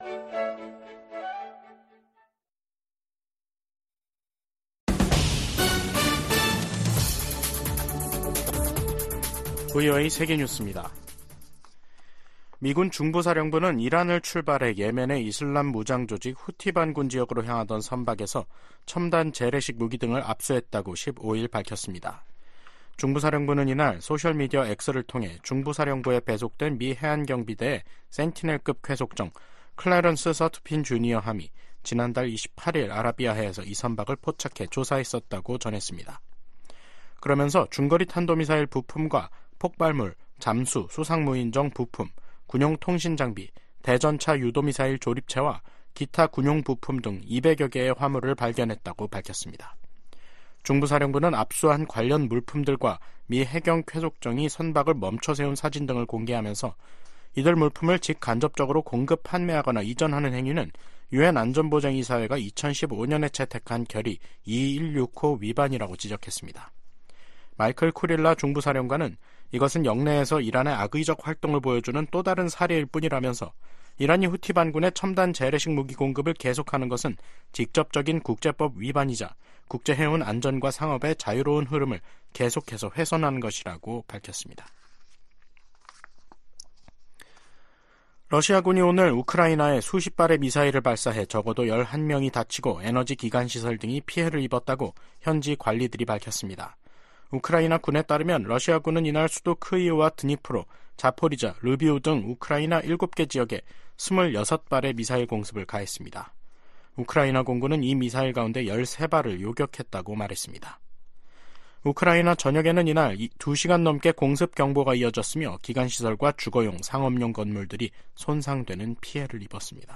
VOA 한국어 간판 뉴스 프로그램 '뉴스 투데이', 2024년 2월 15일 3부 방송입니다. 북한이 신형 지상 대 해상 미사일 시험발사를 실시했다고 관영 매체가 보도했습니다. 미 국무부 고위 당국자가 북한-러시아 밀착에 깊은 우려를 나타내며 다자 협력의 필요성을 강조했습니다.